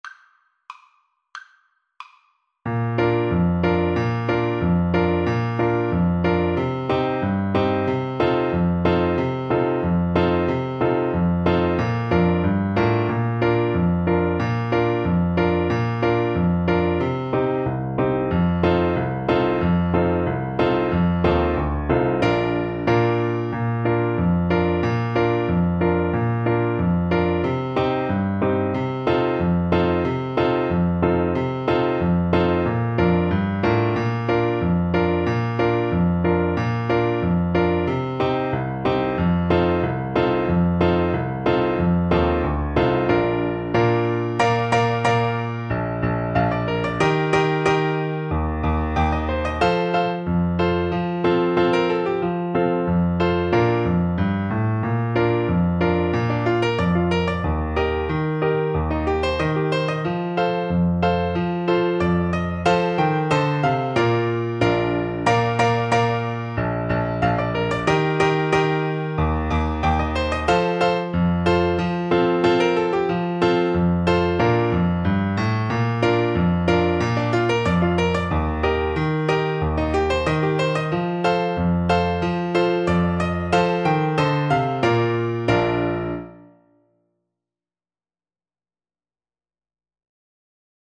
Traditional Music of unknown author.
Moderato =c.92
2/4 (View more 2/4 Music)